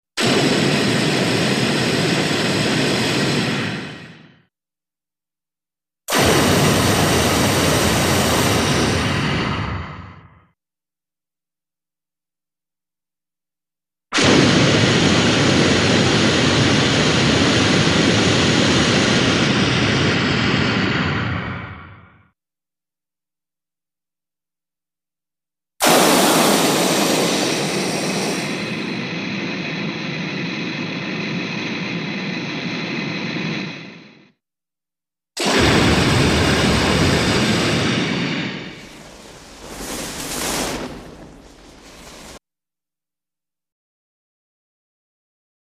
Flame Thrower Blasts, Various Lengths, Metallic Quality, 6x